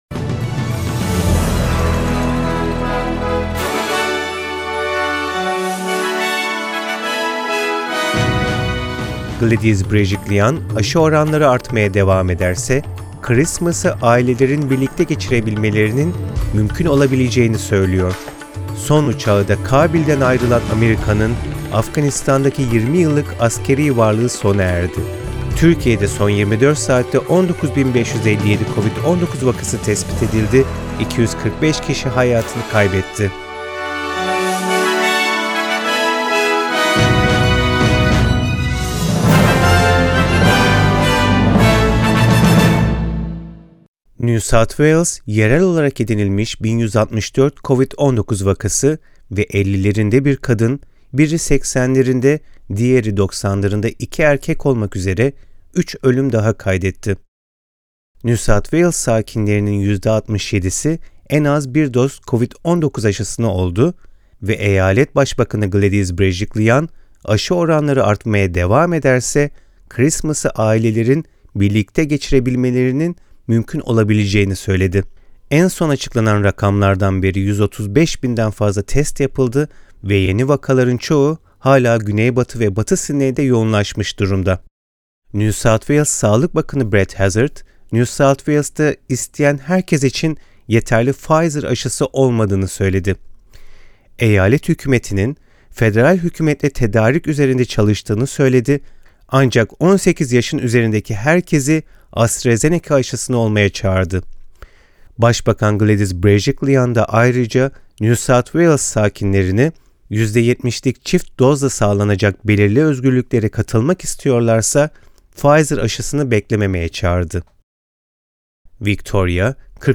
SBS Türkçe Haberler 31 Ağustos